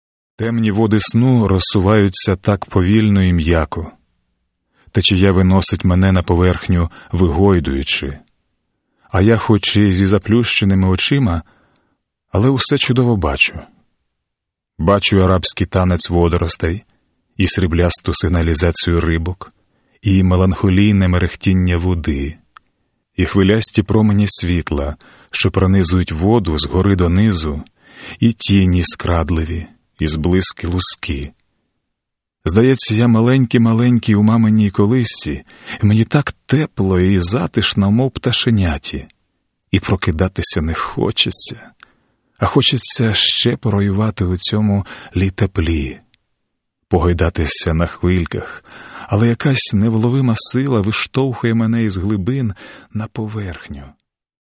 Каталог -> Аудіо книги -> Модерна
Те, що начитати цей роман погодився Народний артист України, лауреат Шевченківської премії Олексій Богданович – є справжнім подарунком для слухачів.
Ведучий актор столичного театру ім. Франка, улюбленець публіки О.Богданович не тільки неперевершено, як на нашу думку, втілив образ головного героя у всіх його протиріччях – розумного і цинічного, нахабного і доброго, розбещеного і в чомусь цнотливого, а  й зумів створити власну версію цілої атмосфери роману."